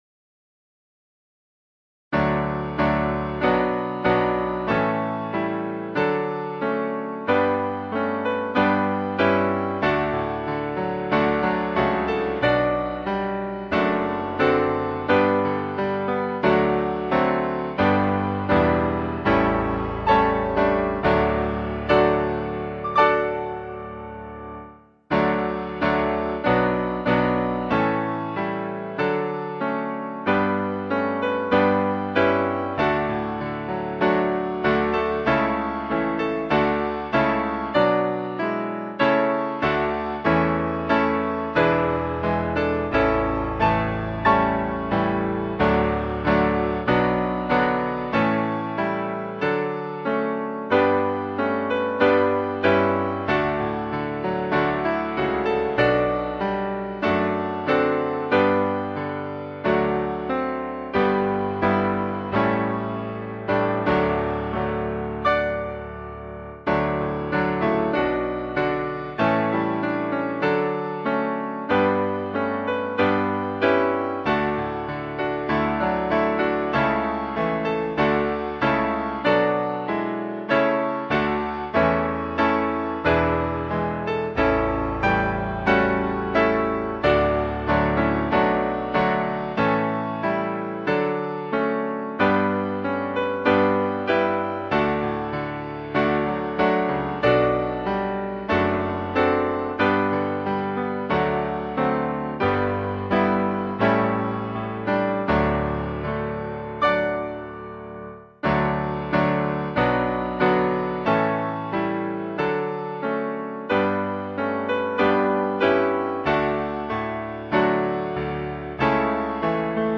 钢琴示范
原唱音频